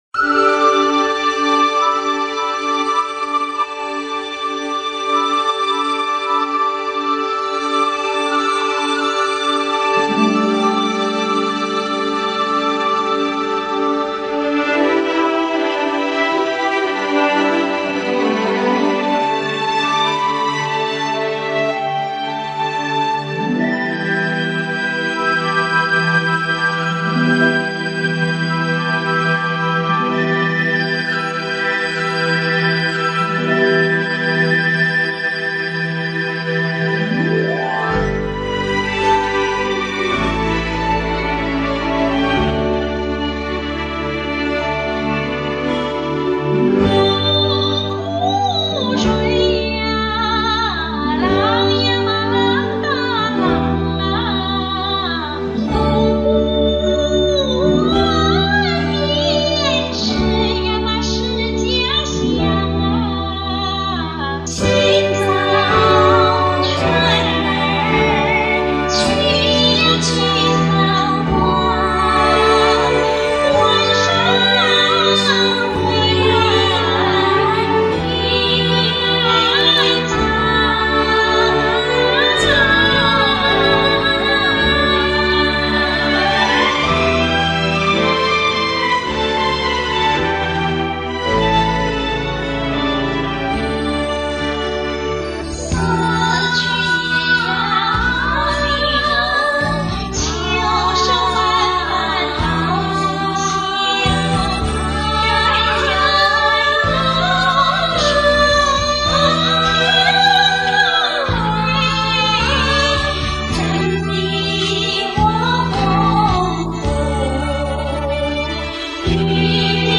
上面俺有份的，算1/4份，两人声音很配，再听
俩美女声音很和谐，优美动听！